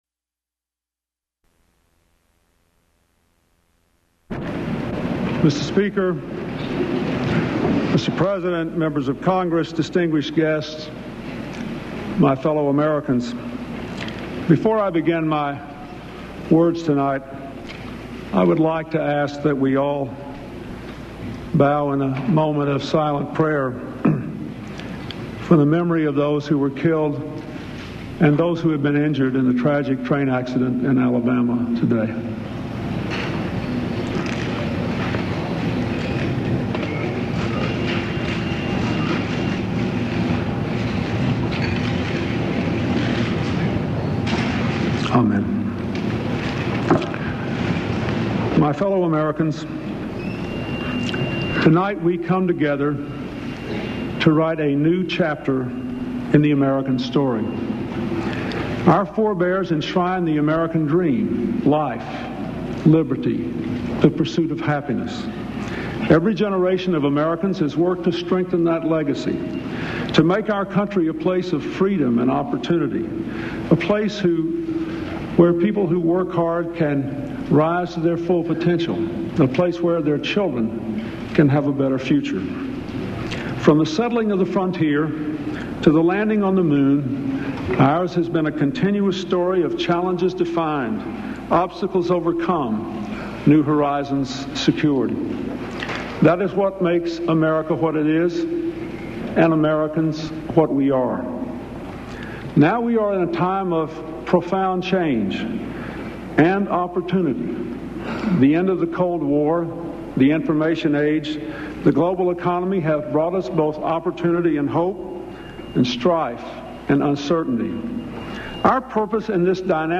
U.S. President Bill Clinton delivers his health care reform message to a joint session of Congress